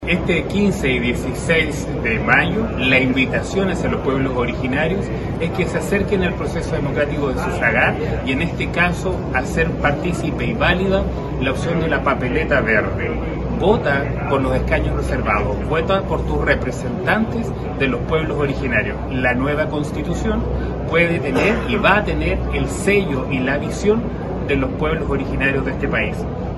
Por este motivo, autoridades nacionales y regionales realizaron un fuerte llamado a la participación en la región de Los Lagos. El Director Nacional de la CONADI, Ignacio Malig señaló que el proceso de fin de semana será histórico.